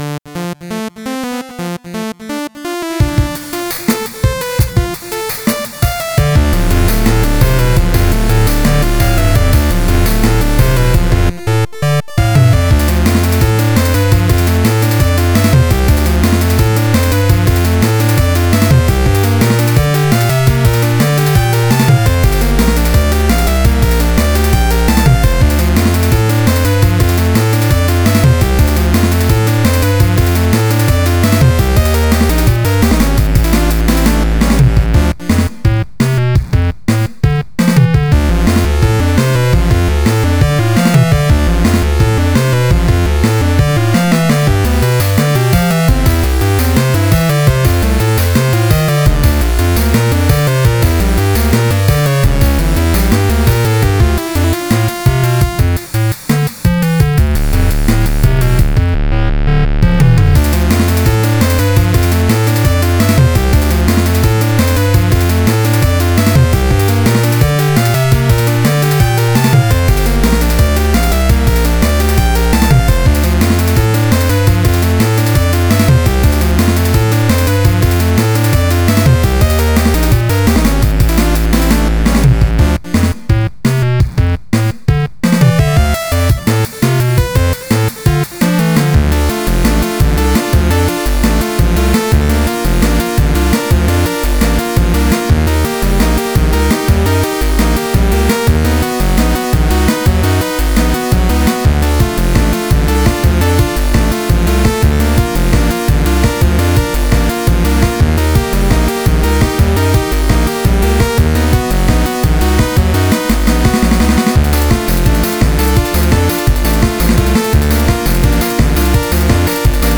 This is a song written in 9/8, somewhat in the spirit of a tune called "Vix 9" by Victor Wooten...well, only in that it's got a similar 9/8 feel -- 2+2+2+3 rather than the typical 3+3+3.
This version uses some synth sounds from Renoise that I was dabbling with.